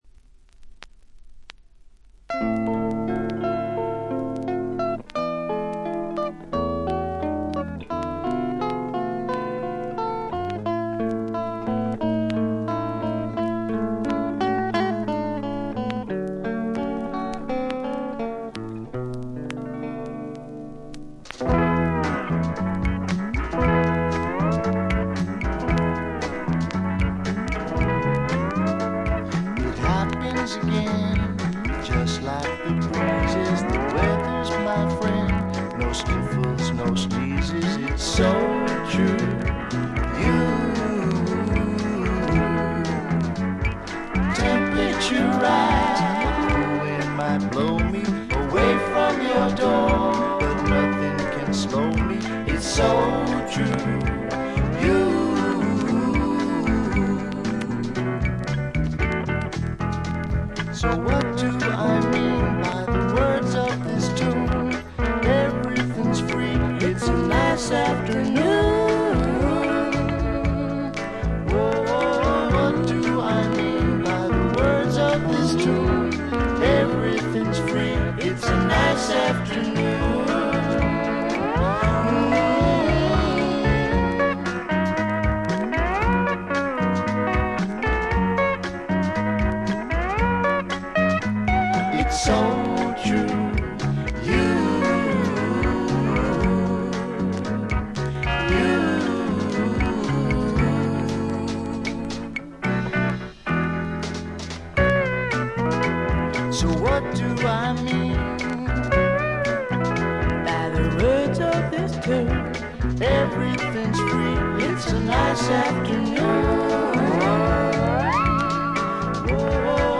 軽微なバックグラウンドノイズ。
このデビュー作はフォーキーなグッタイム・ミュージックの傑作です。
試聴曲は現品からの取り込み音源です。